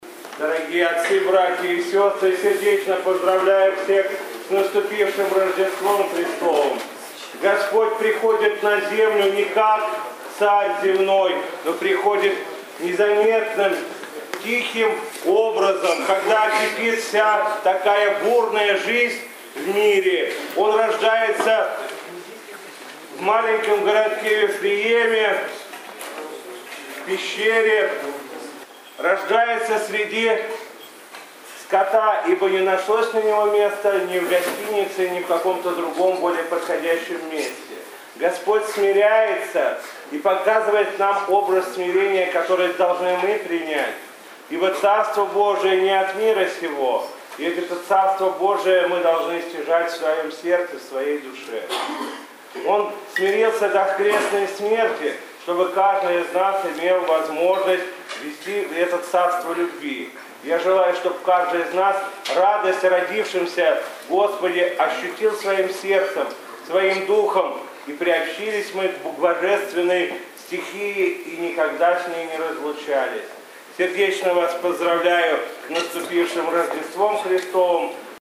Богослужение завершилось славлением перед иконой Рождества, после которого митрополит Игнатий тепло поздравил духовенство и верующих с праздником пришествия в мир Христа Спасителя и обратился к собравшимся с проповедью, посвященной празднику Рождества.